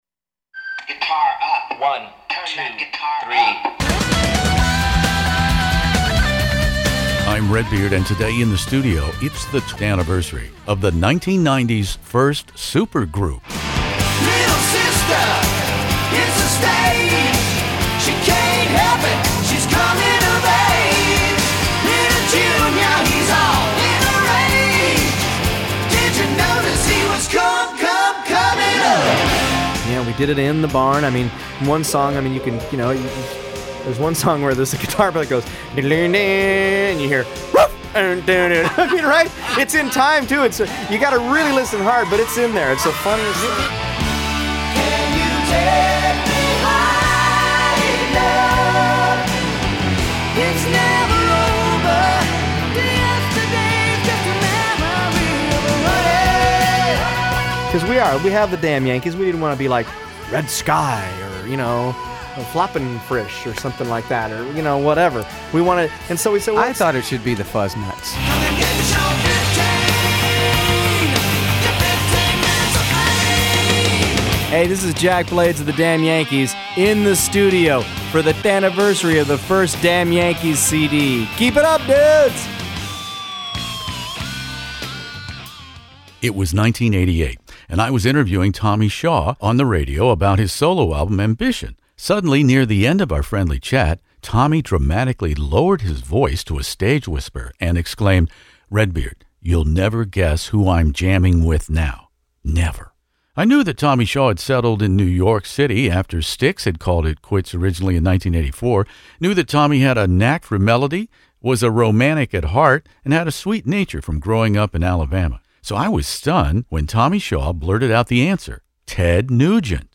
Damn Yankees,the Nineties' first supergroup, interview with Tommy Shaw, Jack Blades, Ted Nugent